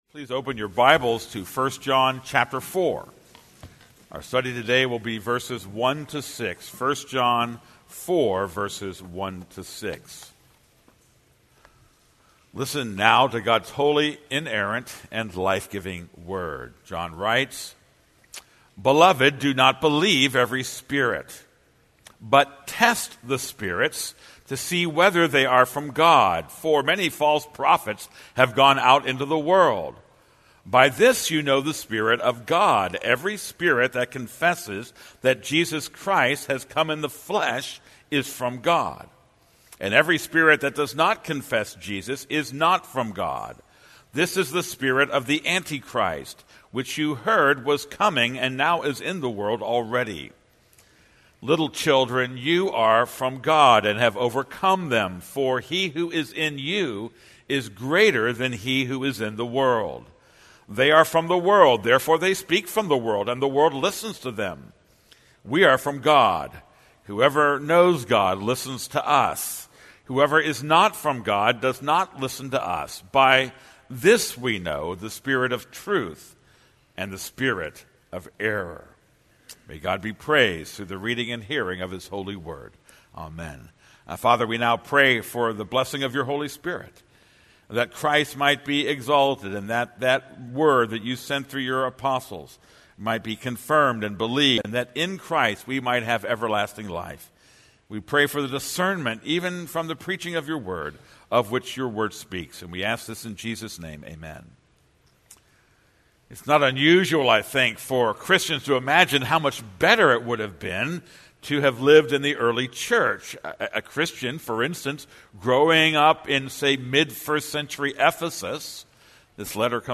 This is a sermon on 1 John 4:1-6.